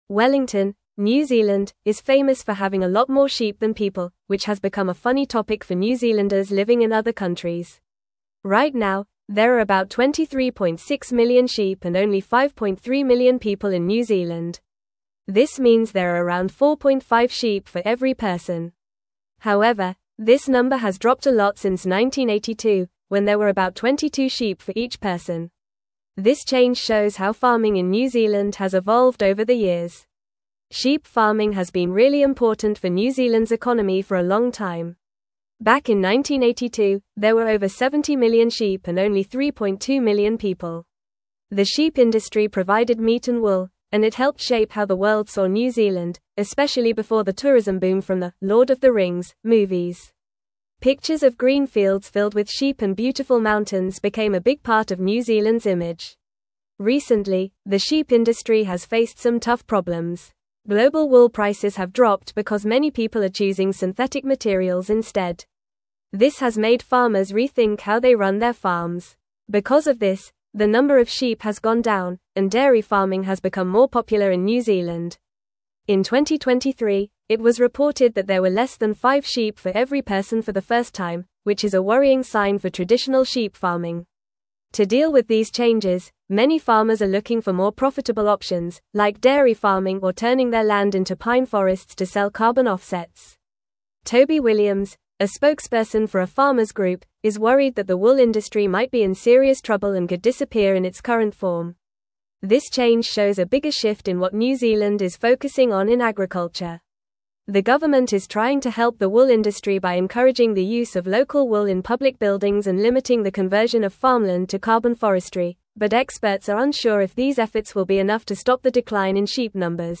Fast
English-Newsroom-Upper-Intermediate-FAST-Reading-Decline-of-New-Zealands-Sheep-Population-and-Industry.mp3